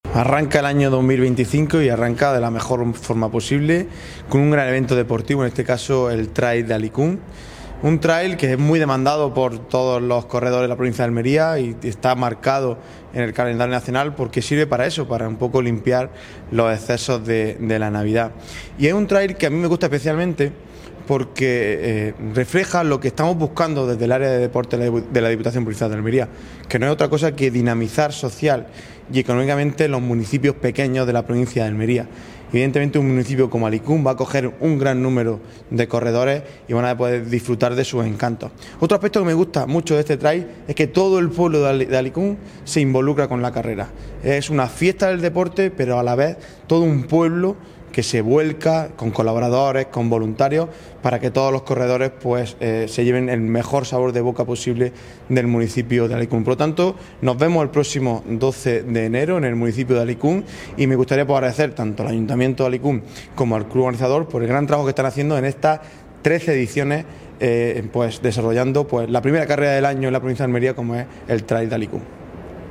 Este martes se ha presentado la edición número trece en el Pabellón Moisés Ruiz.
1Audio-VIII-Trail-Alicun-vicepresidente-y-diputado-de-Deportes.mp3